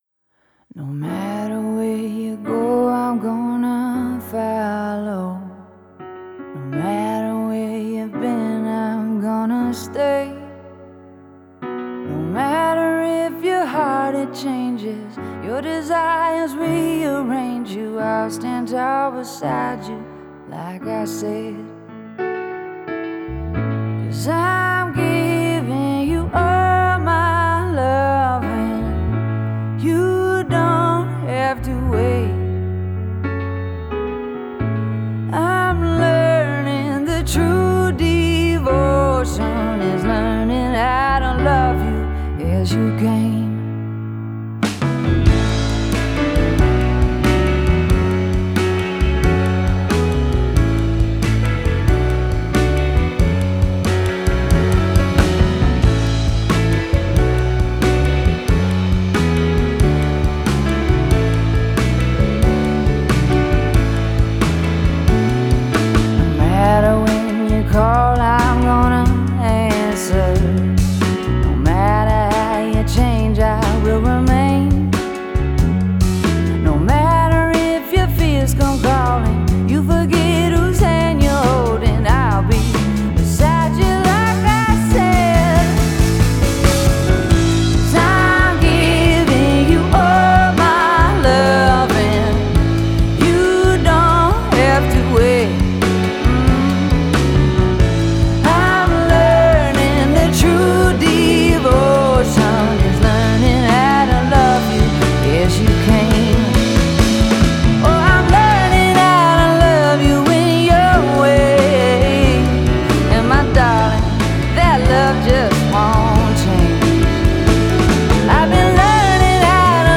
Genre: Indie Pop, Pop Rock,
Singer-Songwriter